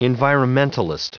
Prononciation du mot environmentalist en anglais (fichier audio)
Prononciation du mot : environmentalist